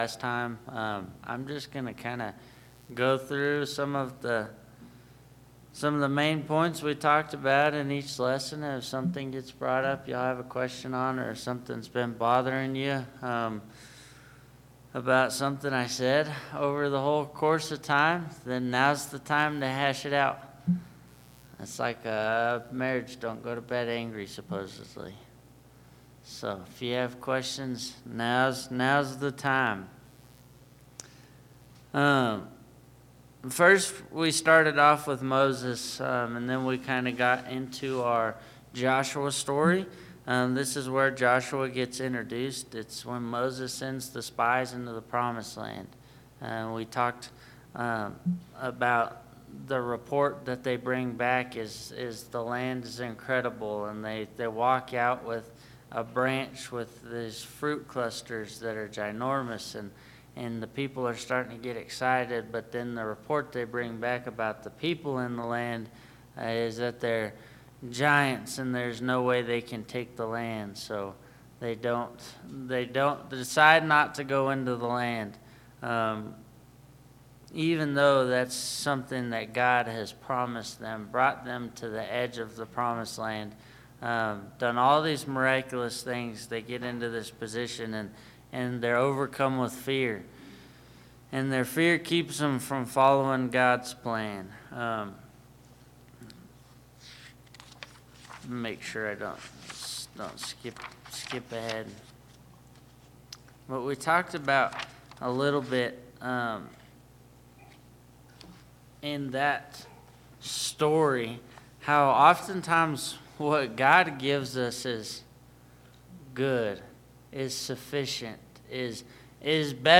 Bible Class 01/12/2025 - Bayfield church of Christ
The audio does pick back up at 5:30 and you can listen to the rest of the class from there.